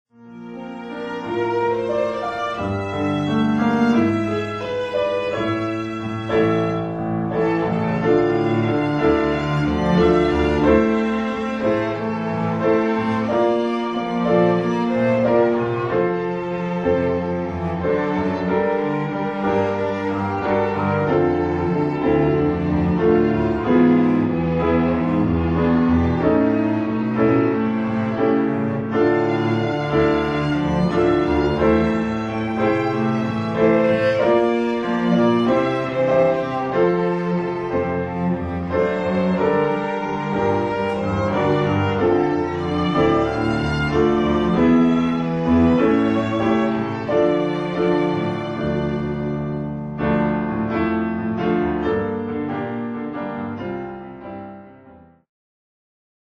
音楽ファイルは WMA 32 Kbps モノラルです。
Violin、Cello、Piano